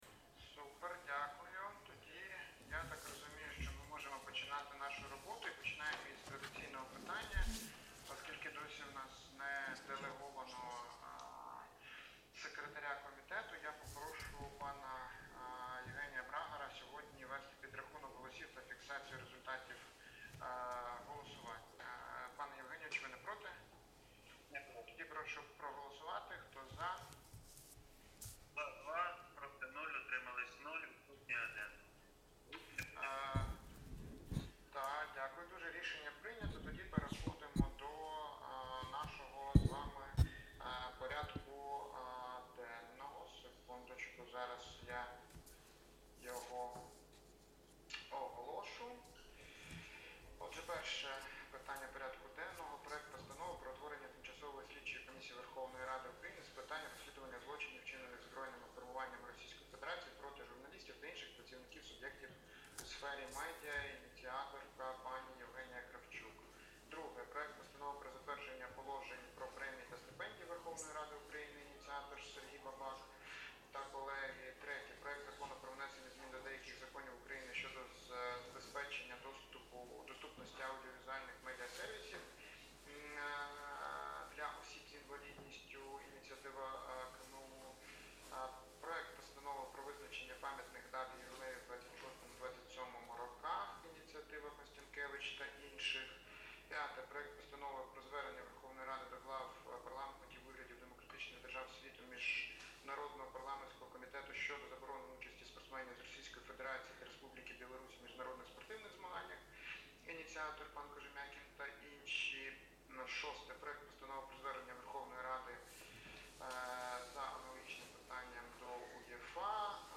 Аудіозапис засідання Комітету від 18 листопада 2025р.